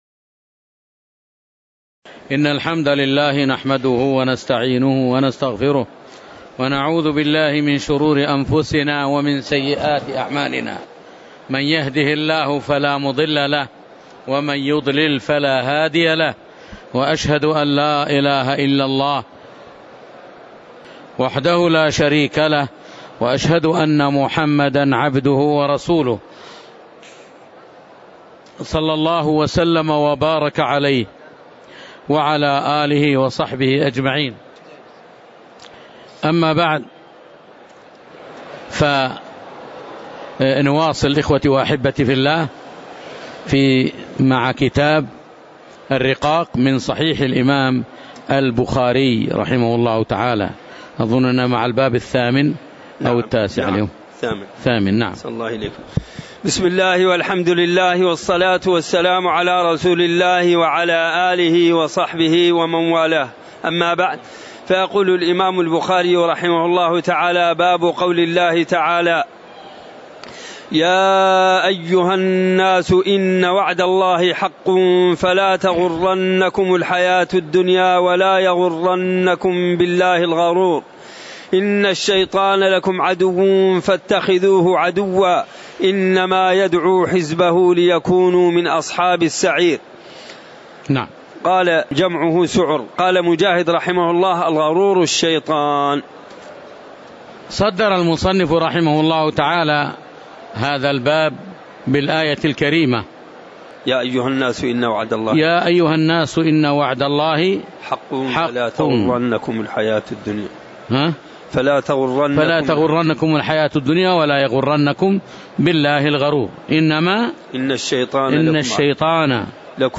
تاريخ النشر ٥ رمضان ١٤٣٩ هـ المكان: المسجد النبوي الشيخ